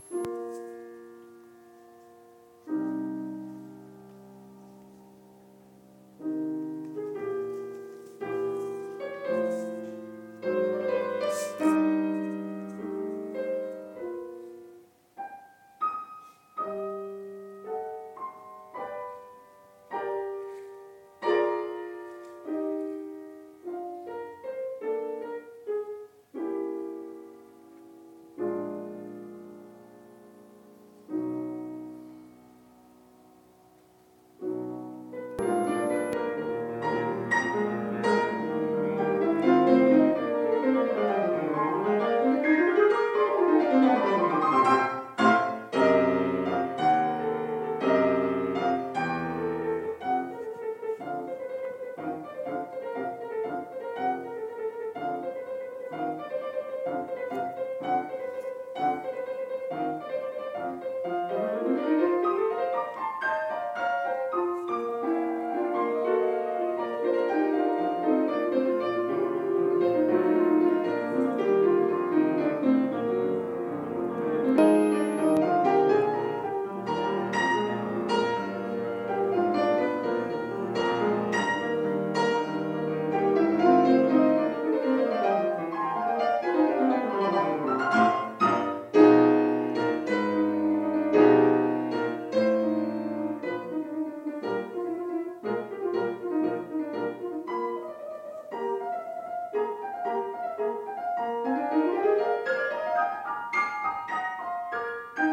kaisersaal klavierkonzert 3